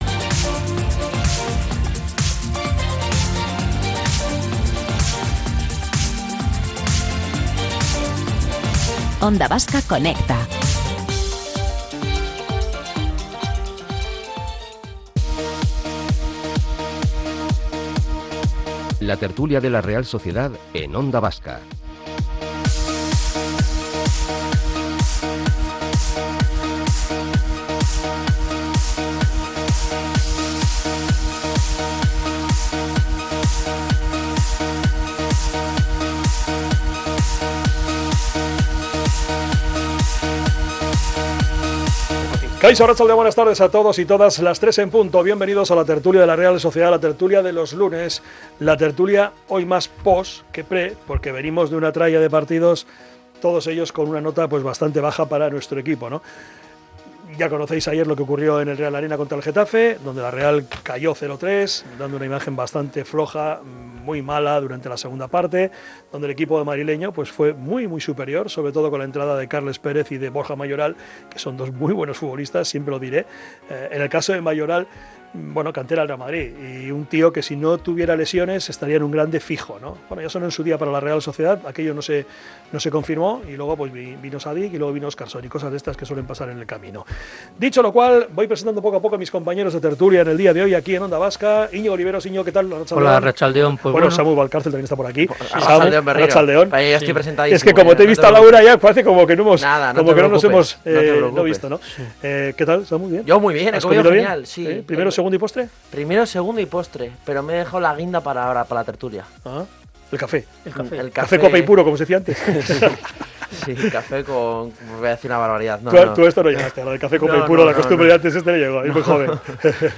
Tertulias